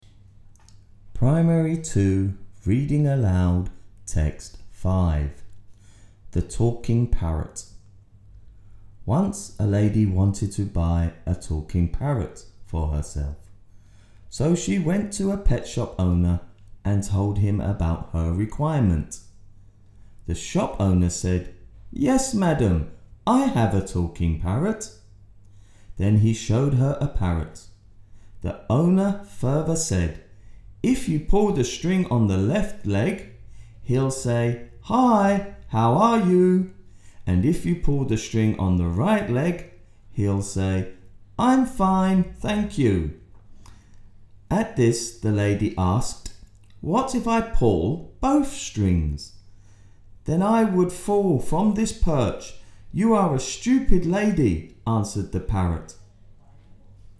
Reading Aloud
แบบฝึกการอ่าน และการออกเสียงภาษาอังกฤษ ปีการศึกษา 2568